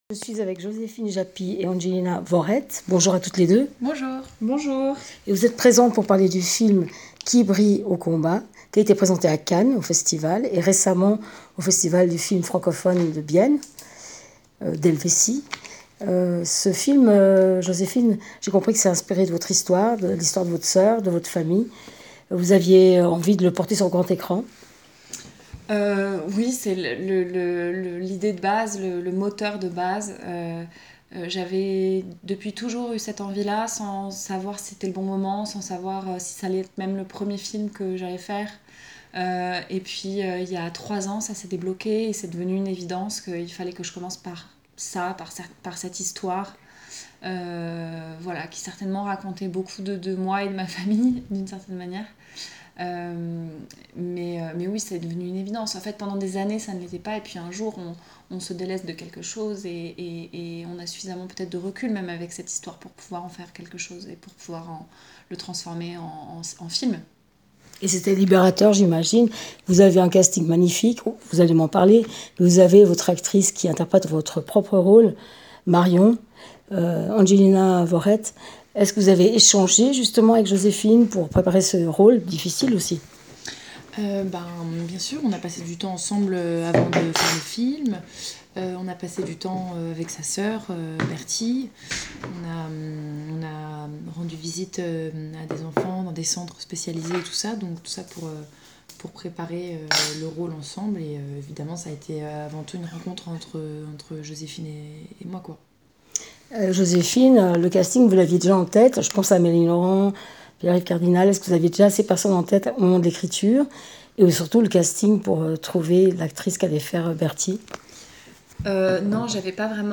Qui brille au combat, de Joséphine Japy : une famille face au handicap, entre amour inconditionnel et tourments personnels. Rencontre